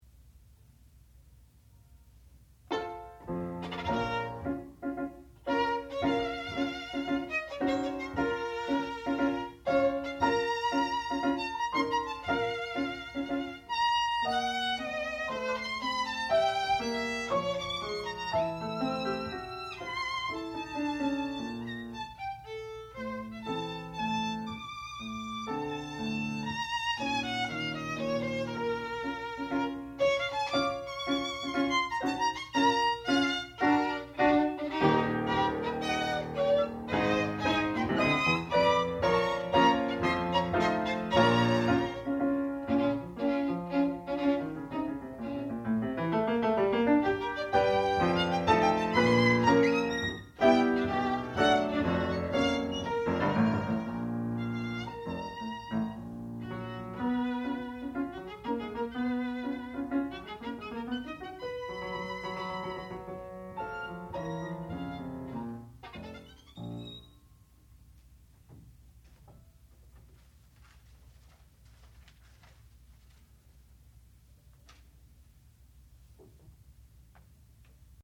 classical music
violin
piano
Master's Recital